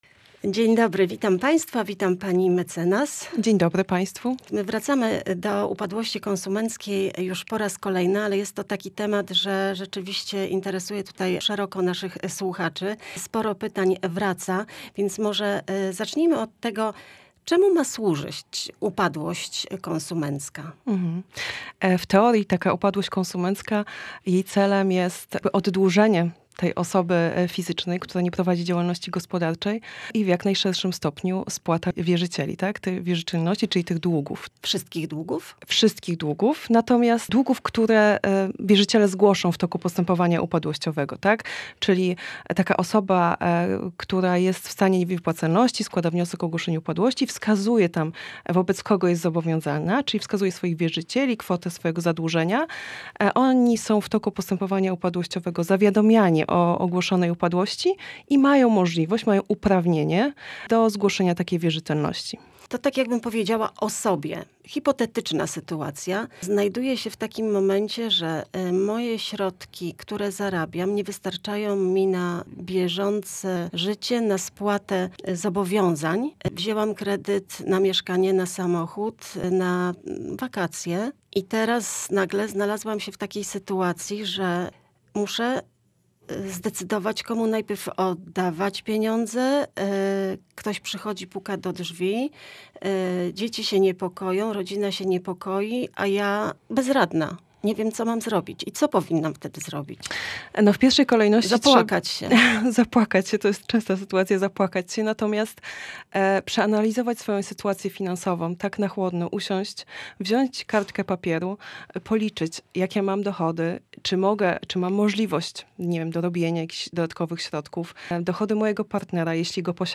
W audycji "Prawo na co dzień" rozmawialiśmy na temat problemów z długami. Jakie kroki można podjąć, aby odzyskać finansową równowagę?